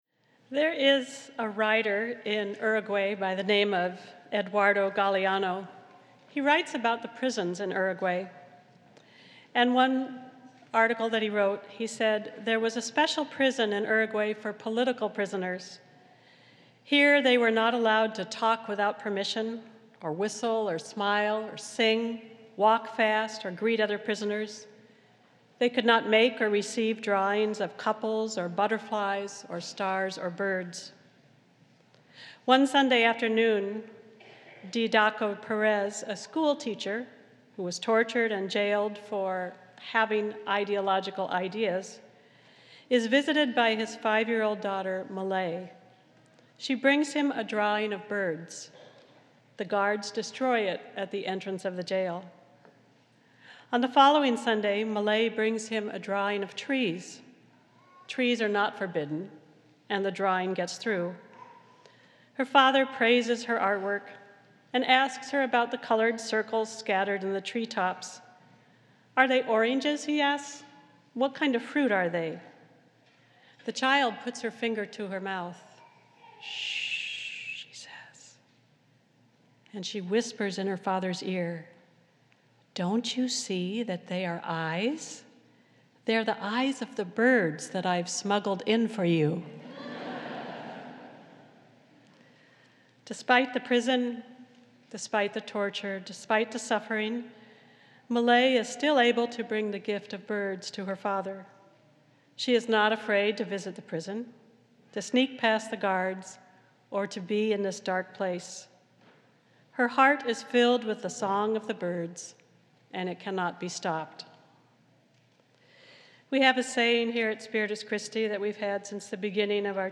This is Easter Sunday at Spiritus Christi in Rochester, NY.
The parishioners are invited to sing the Hallelujah Chorus with the Spirit Singers.